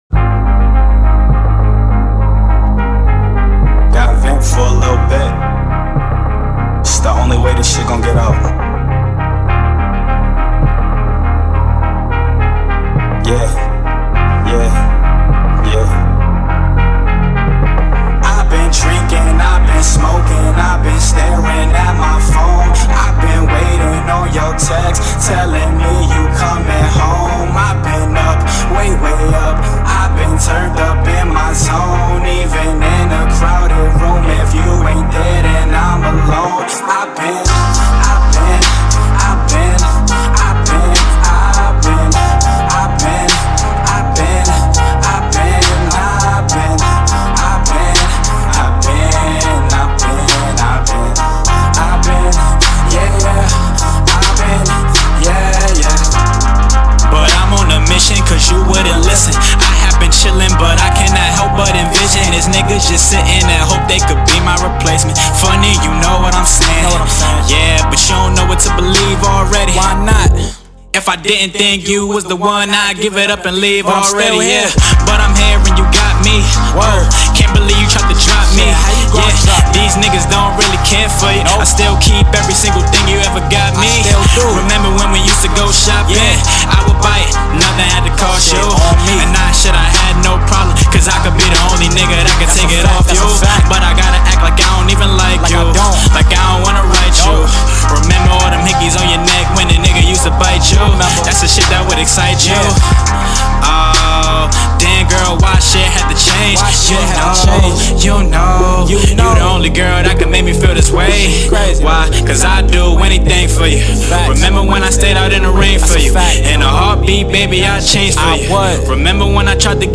Hiphop
New Brooklyn Banger